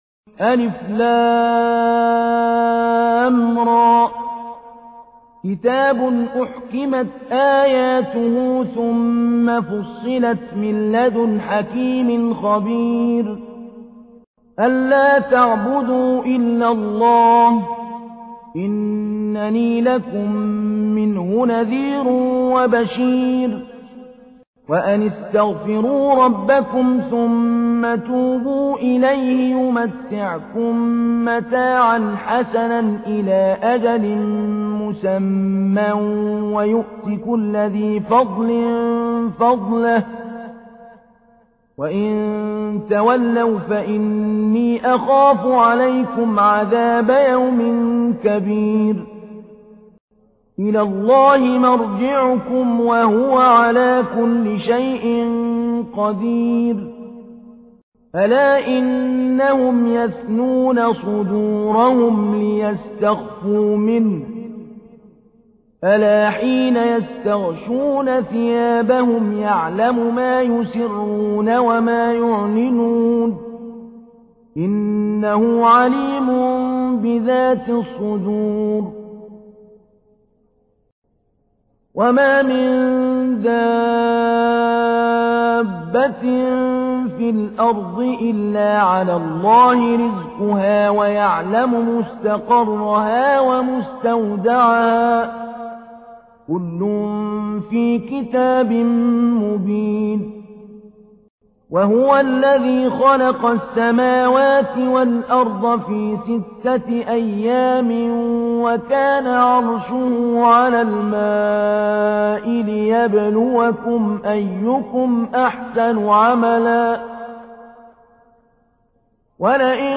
تحميل : 11. سورة هود / القارئ محمود علي البنا / القرآن الكريم / موقع يا حسين